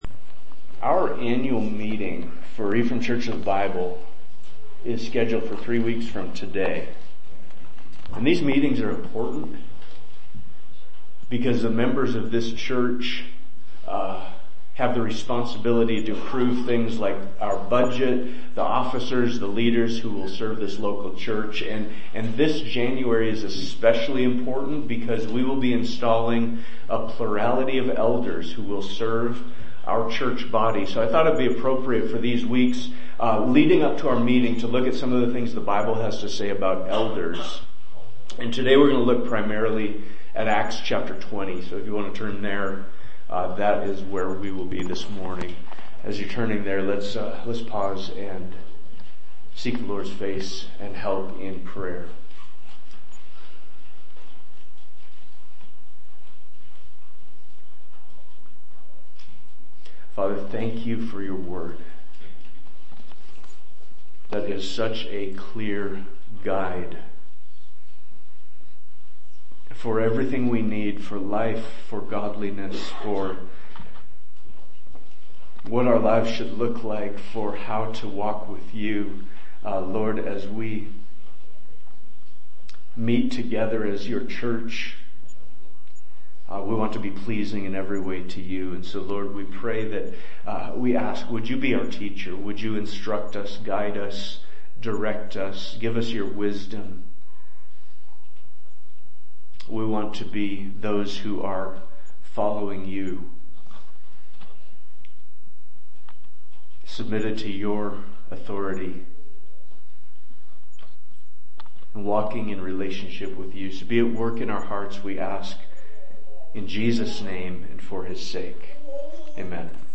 Preaching from the Pulpit of Ephraim Church of the Bible